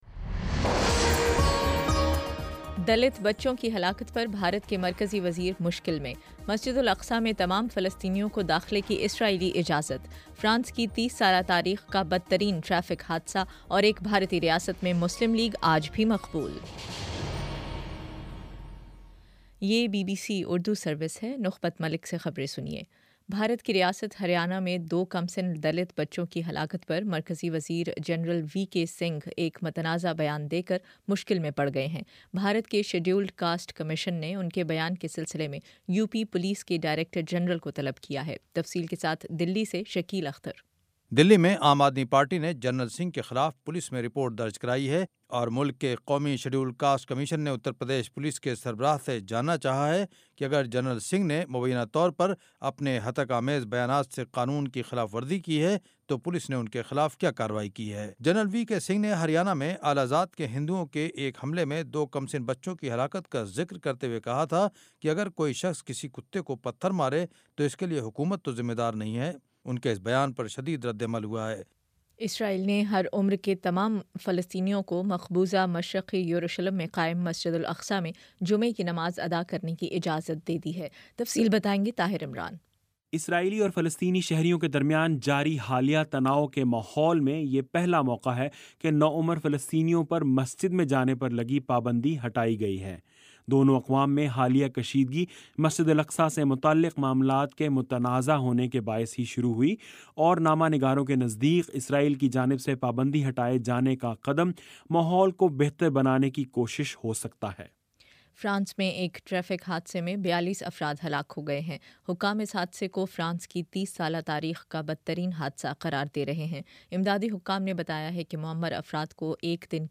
اکتوبر 23 : شام سات بجے کا نیوز بُلیٹن